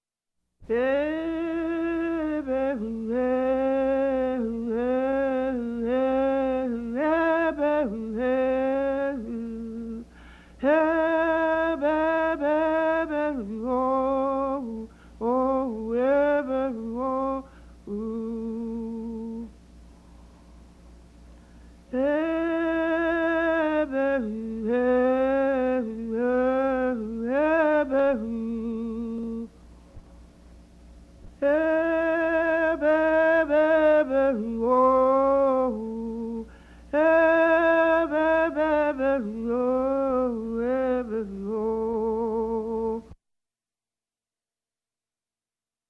Children's Call
childrenscall.mp3